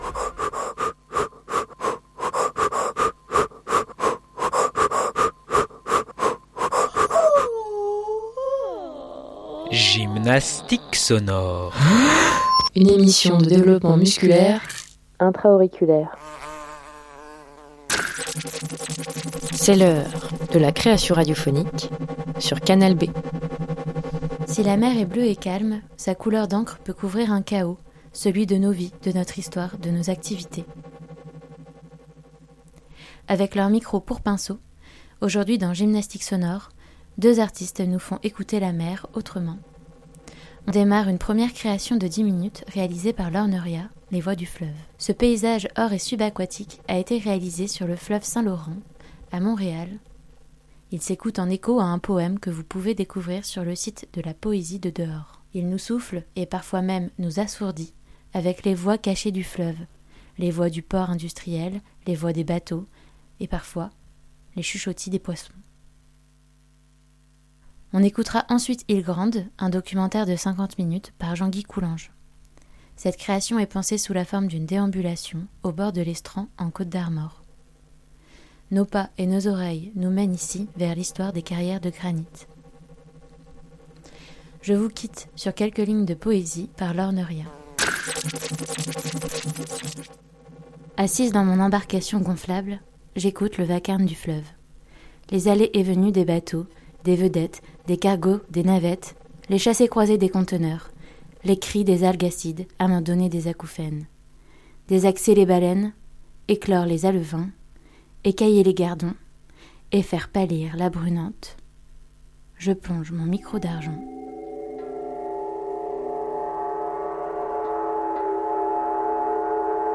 Aujourd'hui dans gymnastique sonore, on écoutera deux créations au bord de l'eau.
Il s'écoute en écho à un poème et nous souffle les voix du fleuve, celle du port, des poissons, d'un bateau.
Cette création est pensée sous la forme d'une déambulation au bord de l'estran en Côte d'Armor, ou nos pas et nos oreilles nous mènent vers l'histoire des carrières de granit.